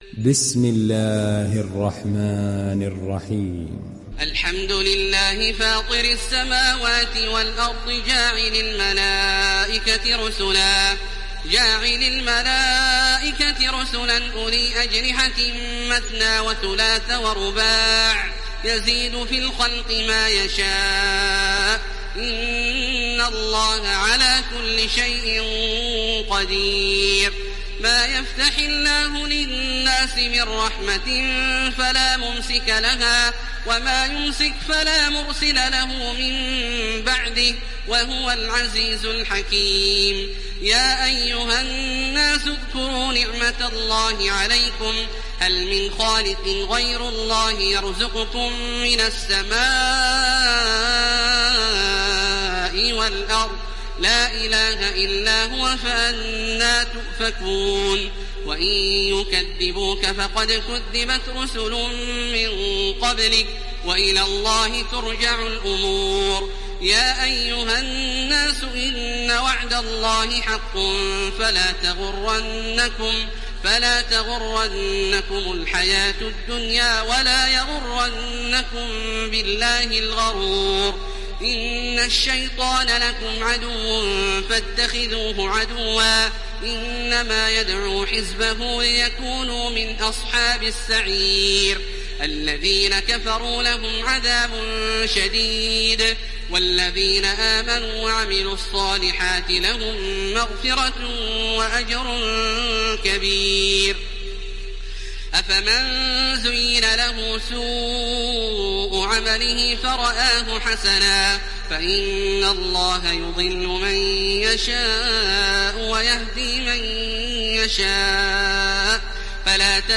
Télécharger Sourate Fatir Taraweeh Makkah 1430
Hafs an Assim